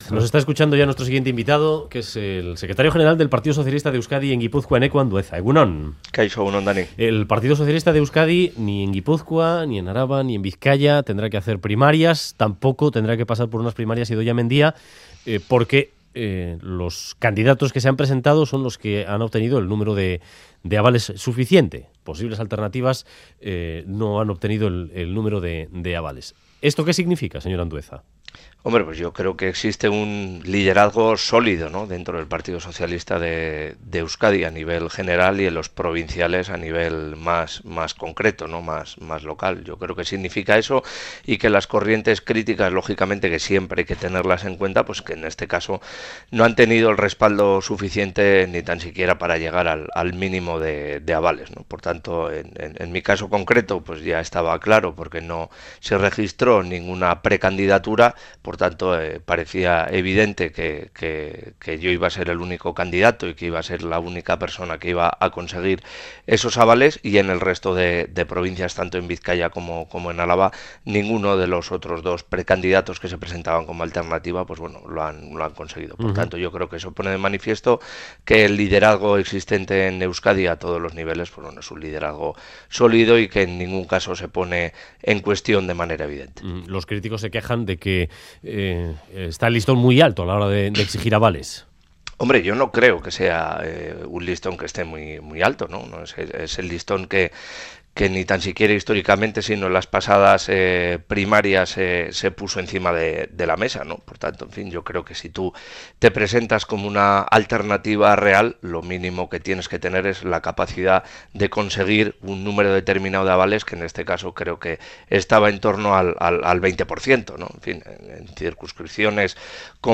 Entrevista Eneko Andueza